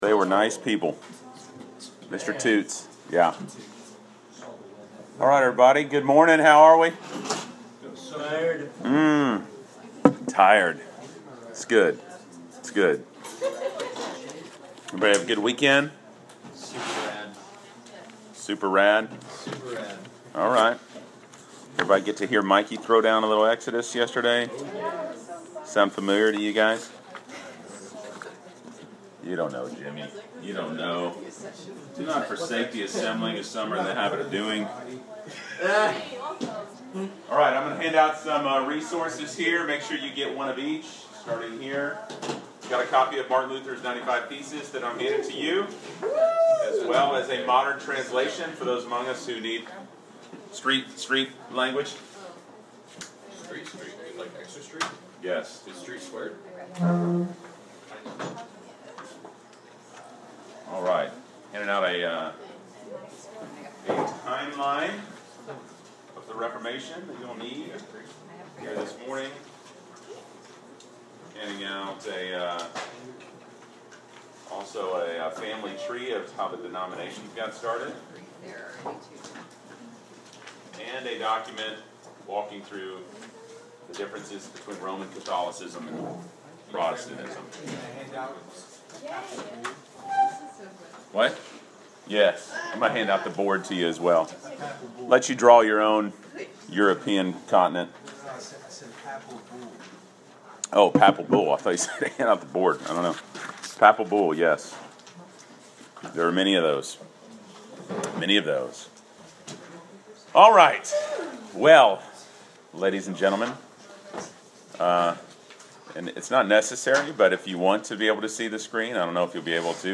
Class Session Audio February 13